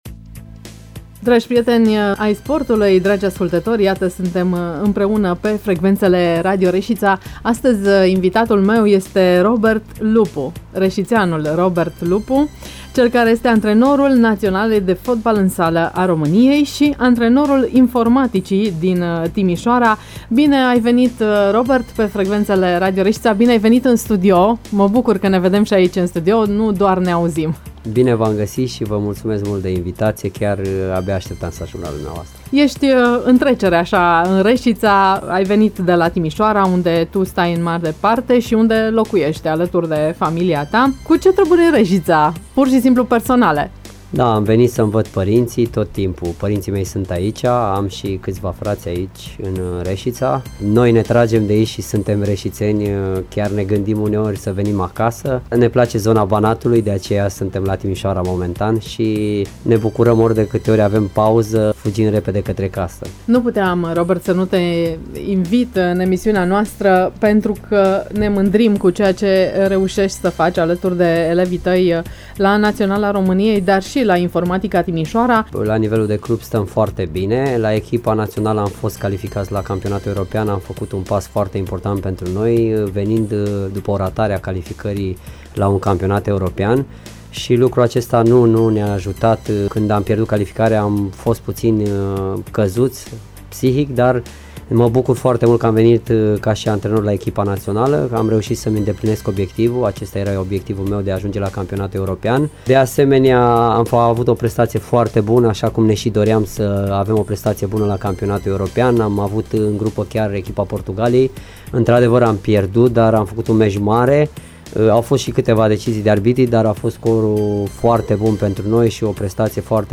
Prezent în studioul Radio Reșița a afirmat: